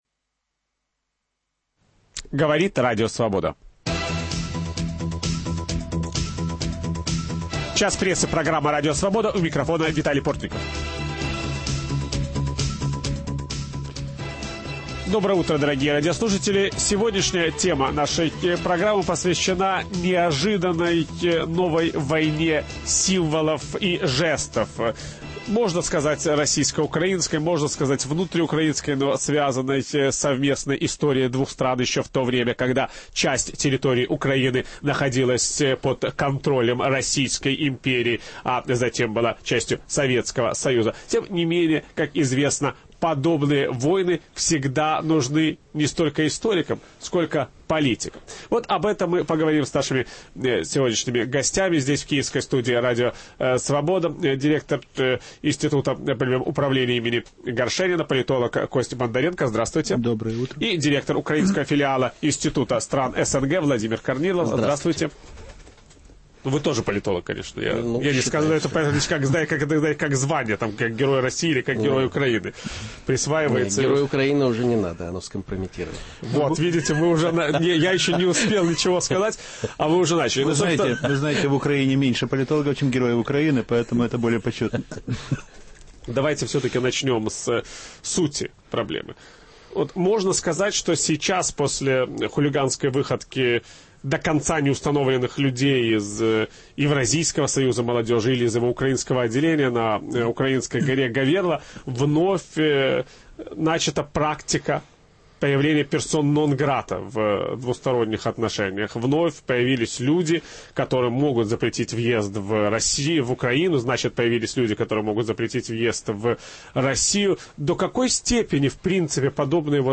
Кому нужна новая украинская «война символов»? Об этом беседа с политологами